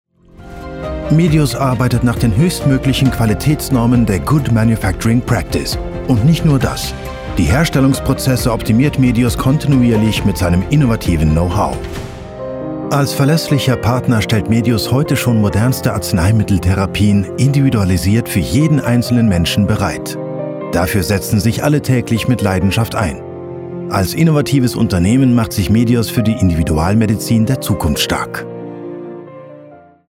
Demo comercial
Vídeos explicativos
Micrófono: Neumann TLM 103 / Interfaz de audio: Solid State Logic (SSL-2+) / Popkiller: K&M 23956 / DAW: Cubase Elements 11 / Wavelab 11
Cabina de sonido: "Sala en sala"
BarítonoBajoProfundoBajoMuy bajo